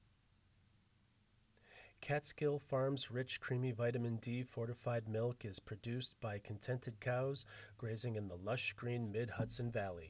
My peaks only reach about -25. I have the mic cranked to 100 both in Audacity and in the laptop’s control panel.
I’m using an aged Audio-Technica ATR2100 microphone.
Now I have the mic suspended a few inches from my mouth, as advised by the ACX audiobook recording tutorials.